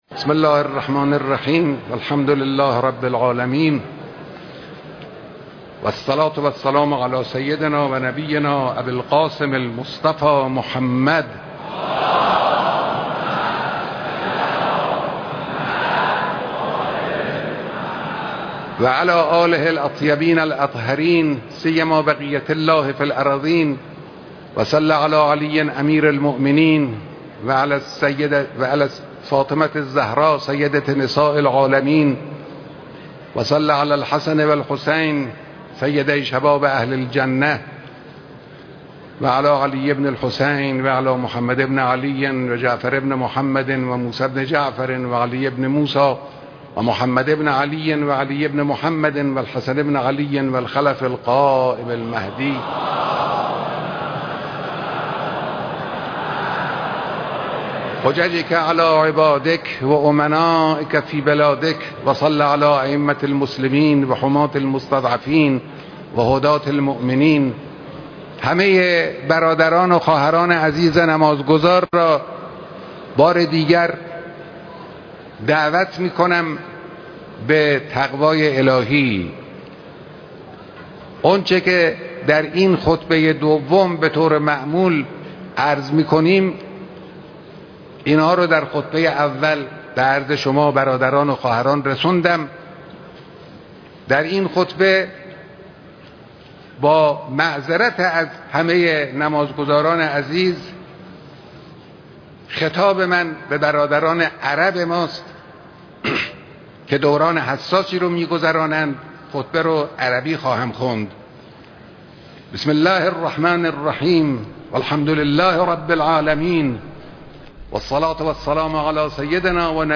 دانلود خطبه تاريخي مقام معظم رهبري در نماز جمعه تاريخ 14/11/1390